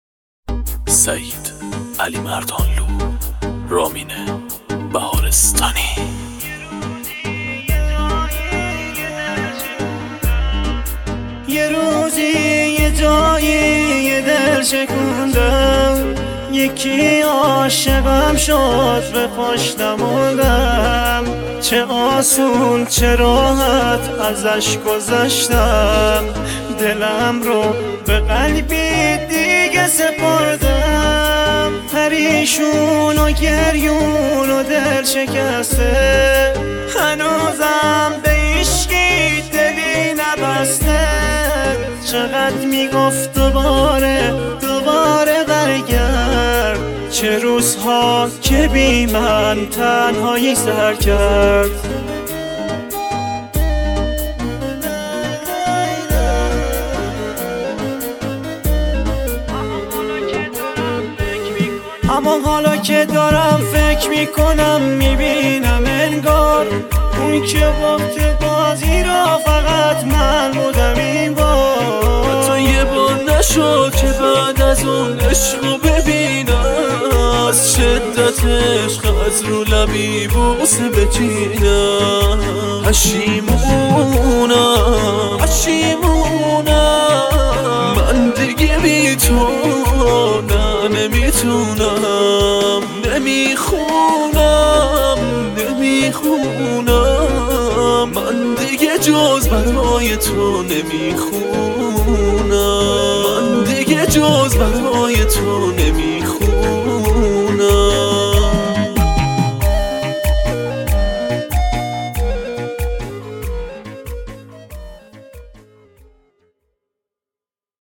ترانه بسیار سوزناک و غمگین
با صدای مشترک